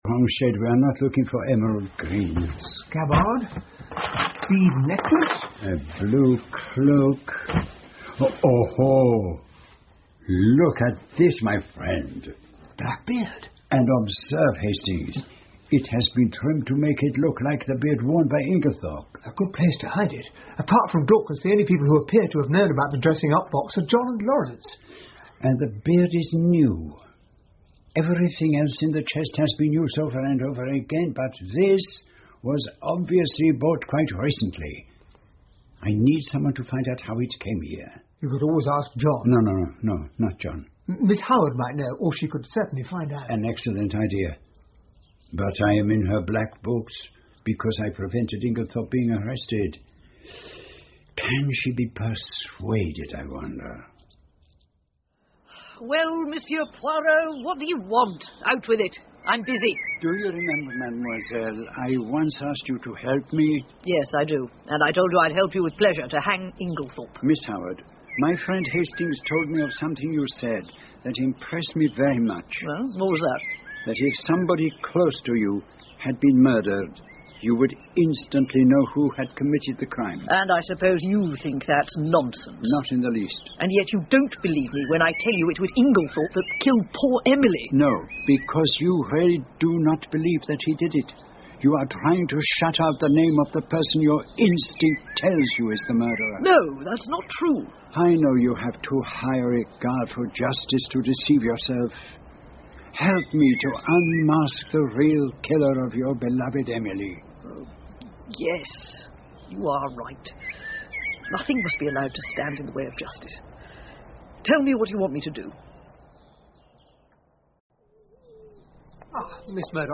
在线英语听力室英文广播剧在线听 Agatha Christie - Mysterious Affair at Styles 15的听力文件下载,英语有声读物,英文广播剧-在线英语听力室